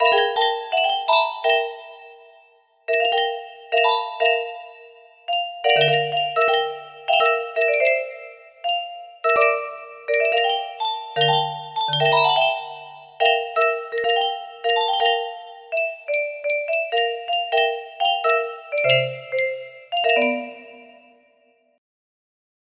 2 music box melody